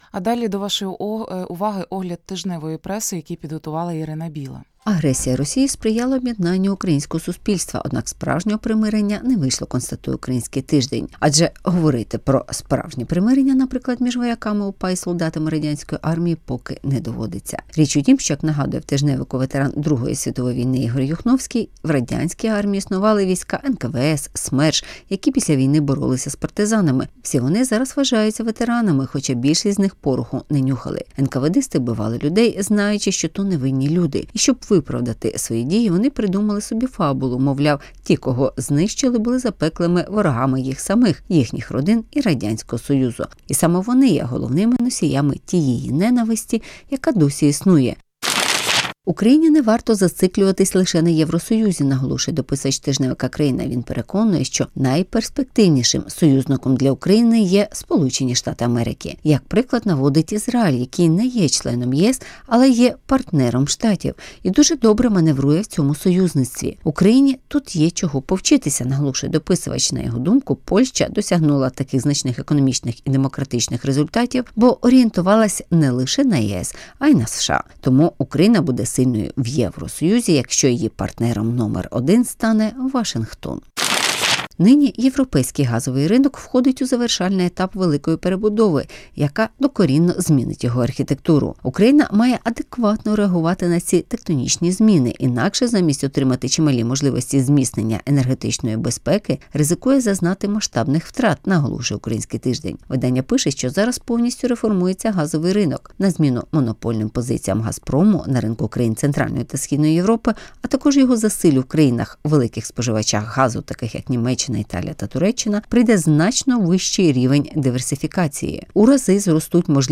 Хто досі роздмухує ненависть до ветеранів УПА? (Огляд преси)